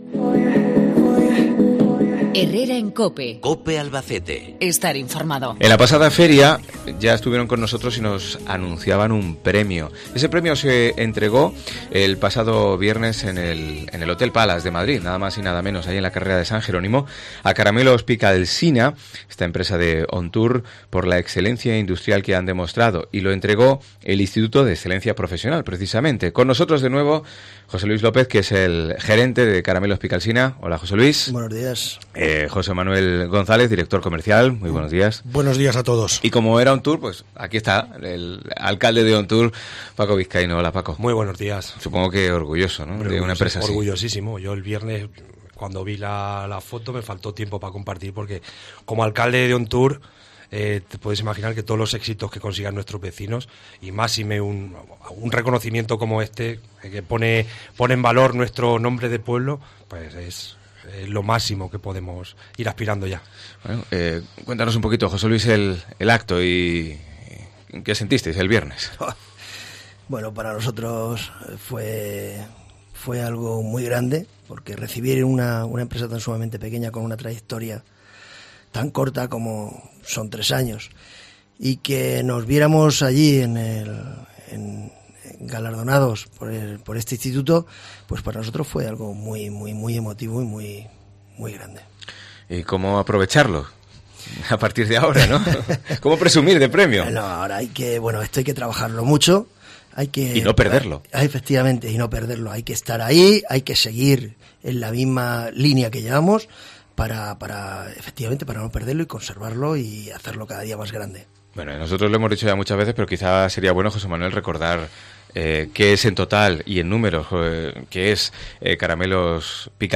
Entrevista en COPE